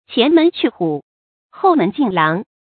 前门去虎，后门进狼 qián mén qù hǔ，hòu mén jìn láng 成语解释 比喻赶走了一个敌人，又来了一个敌人。
ㄑㄧㄢˊ ㄇㄣˊ ㄑㄩˋ ㄏㄨˇ ，ㄏㄡˋ ㄇㄣˊ ㄐㄧㄣˋ ㄌㄤˊ